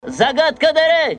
голосовые